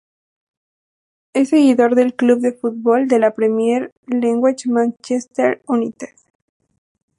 se‧gui‧dor
/seɡiˈdoɾ/